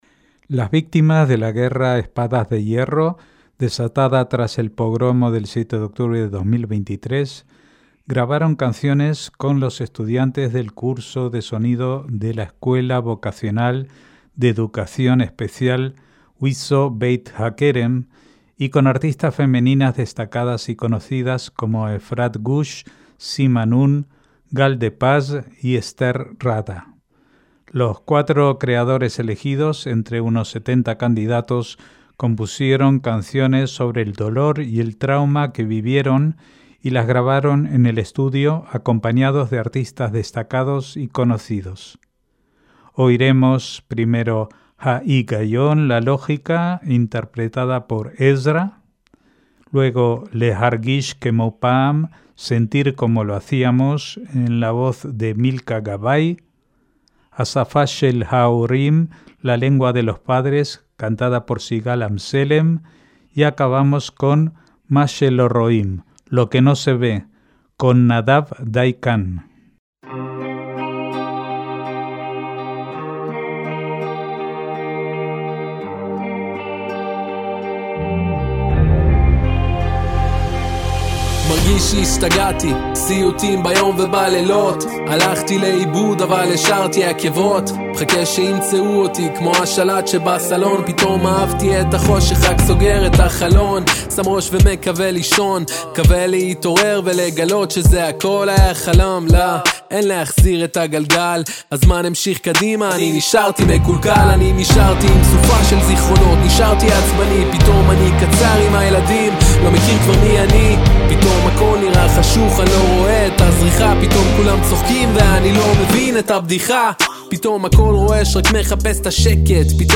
MÚSICA ISRAELÍ
Los cuatro creadores elegidos, entre unos 70 candidatos, compusieron canciones sobre el dolor y el trauma que vivieron, y las grabaron en el estudio acompañados de artistas destacados y conocidos.